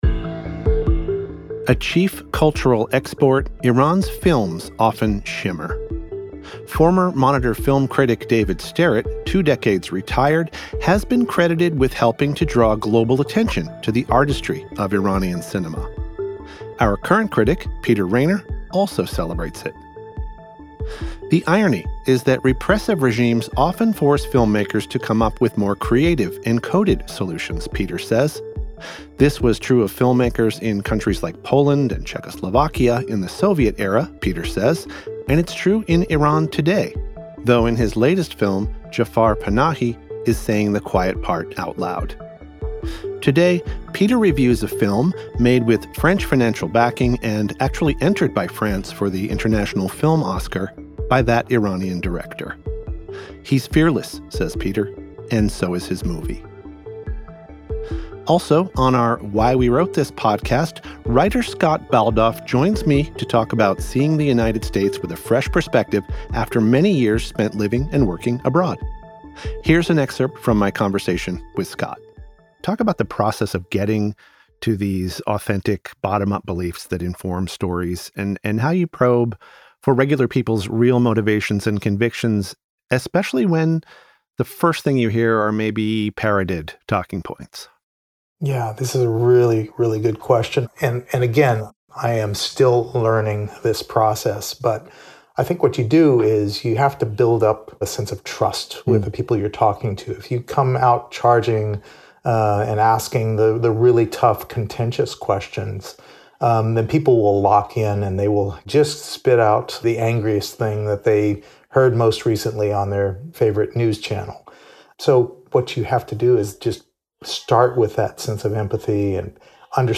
The editors of The Christian Science Monitor take you beyond the headlines with the ideas driving progress in this 15-minute news briefing.